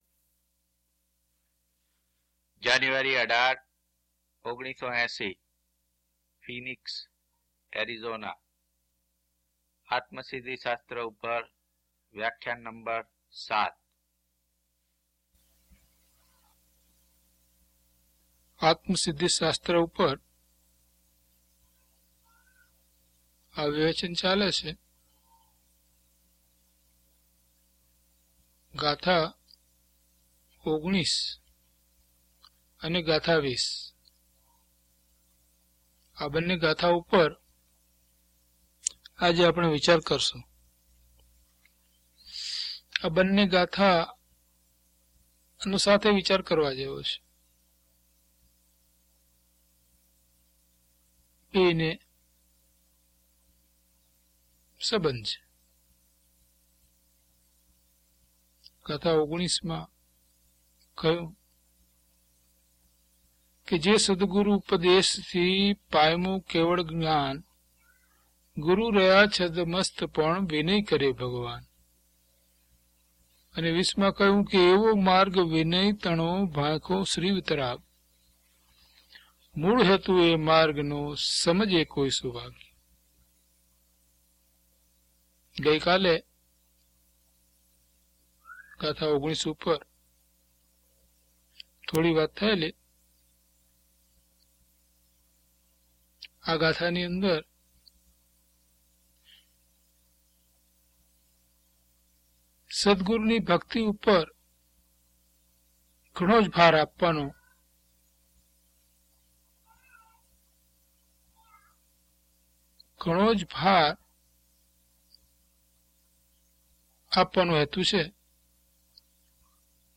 DHP016 Atmasiddhi Vivechan 7 - Pravachan.mp3